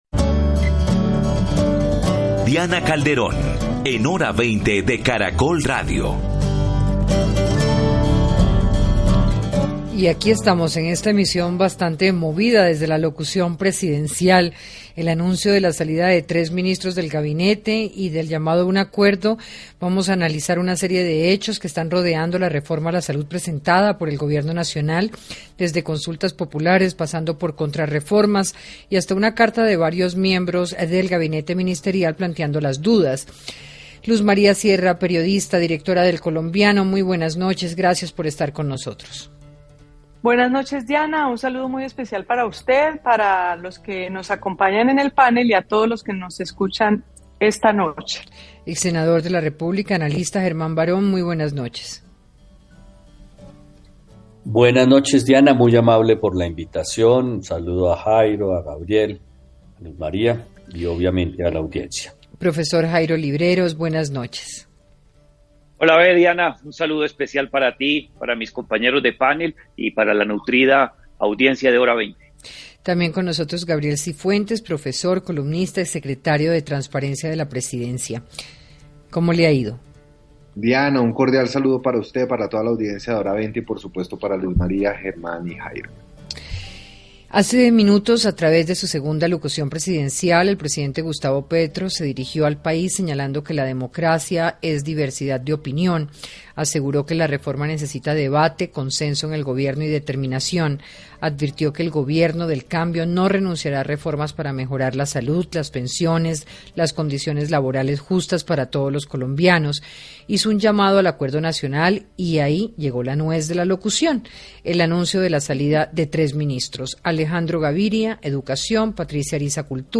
Panelistas creen que el gobierno deberá reforzar la capacidad de negociación.